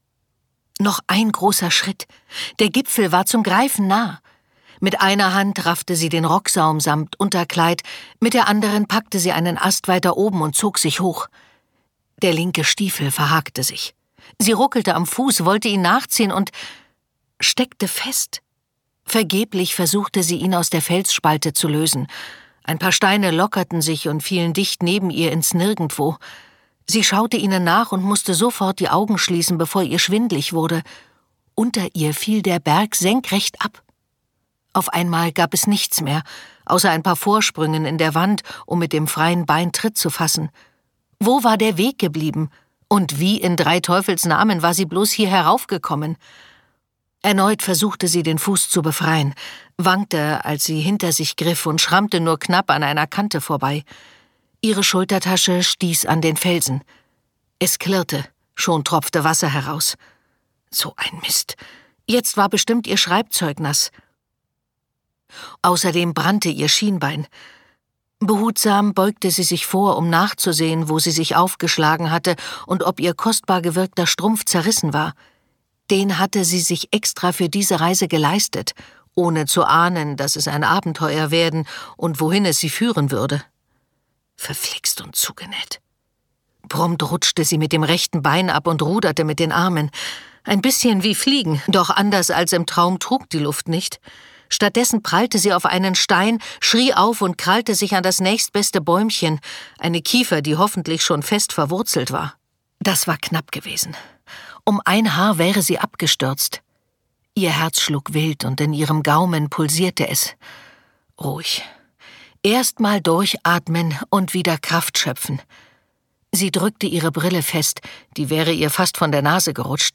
Morgen sind wir wild und frei - Stephanie Schuster | argon hörbuch